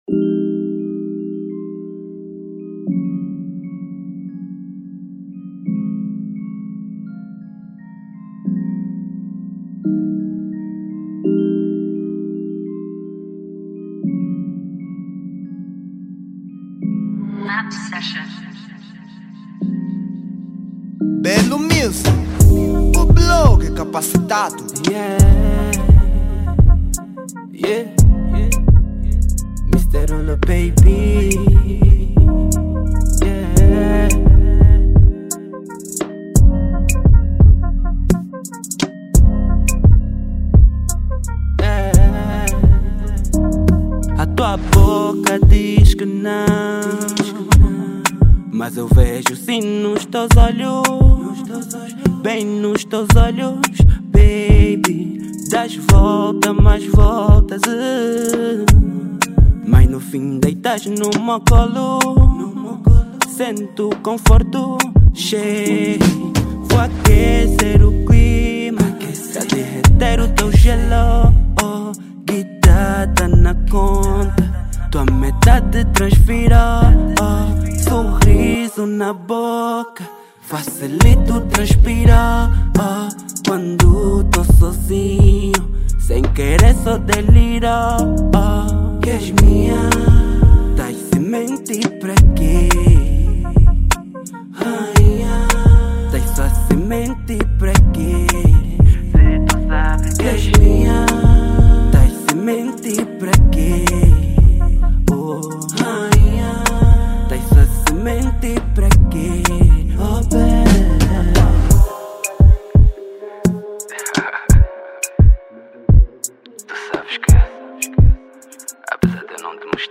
Género : Zouk